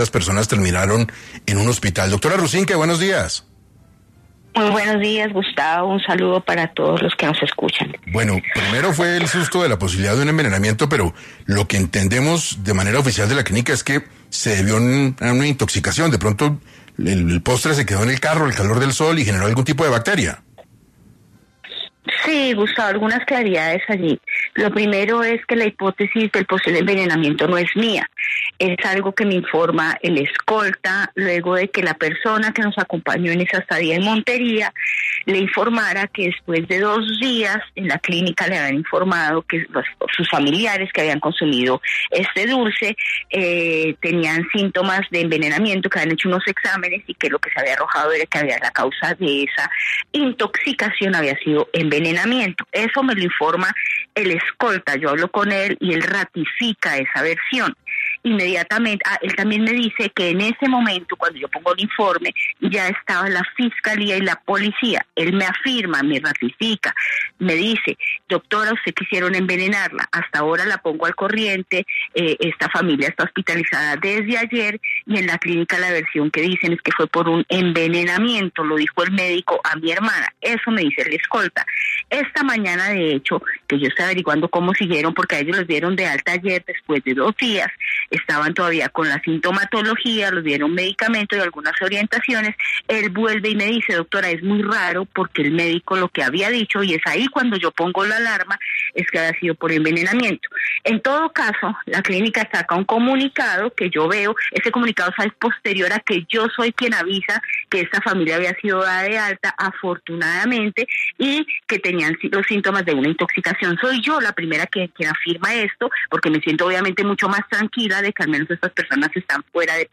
En entrevista para 6AM, la ministra de comercio, Cielo Rusinque, se pronunció ante su supuesto intento de envenenamiento, ¿fue un accidente o un atentado?